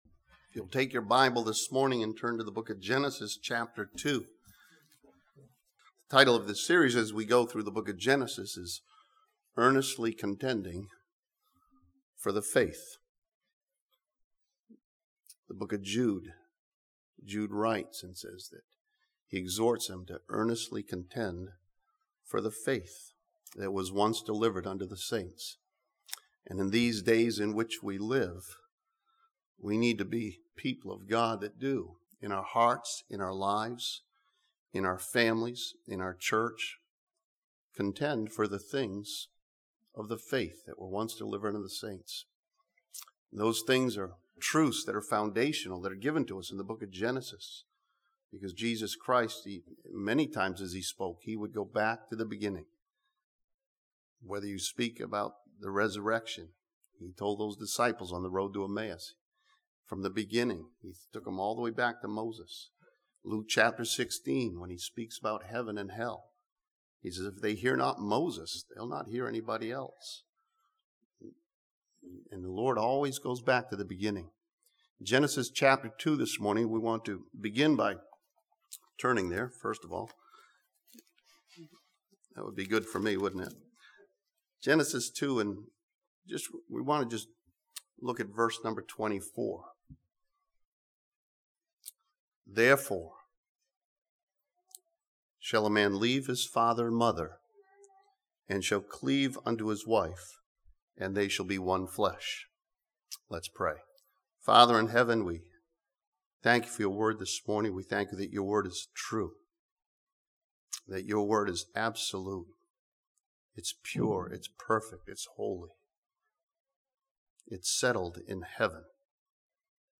This sermon continues in our Contending for the Faith series with a study of the miracle of marriage from Genesis chapter 2.